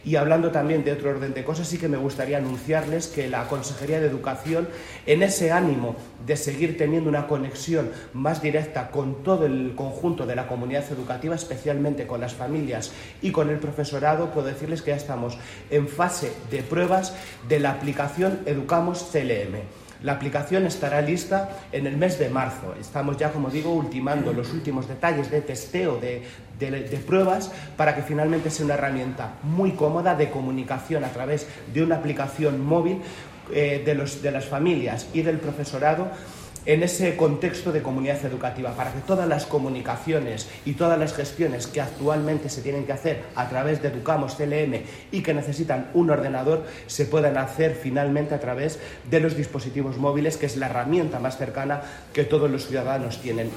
Lo ha avanzado el consejero de Educación, Cultura y Deportes, Amador Pastor, en la inauguración, a cargo del presidente de Castilla-La Mancha, de la ampliación de la Escuela Infantil ‘Arco Iris’ de Socuéllamos (Ciudad Real).
En sus declaraciones ante los medios de comunicación, Amador Pastor ha comentado que la aplicación, actualmente en fase de testeo, pretende ser una herramienta de “comunicación y seguimiento educativo” de los hijos para las familias, además de “un punto de acceso” para la gestión docente.